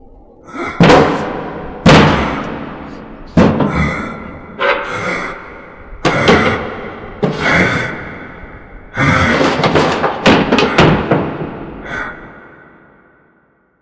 scream_7.wav